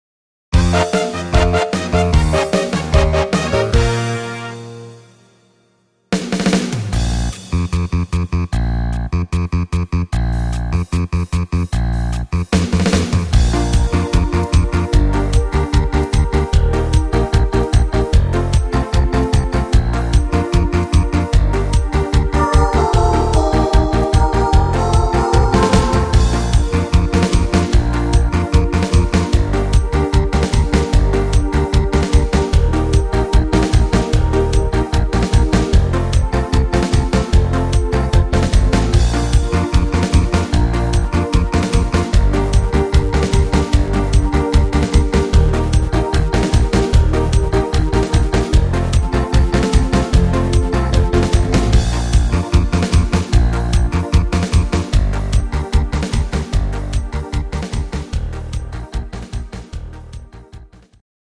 Rhythmus  Dancefloor Cha cha
Art  Pop, Englisch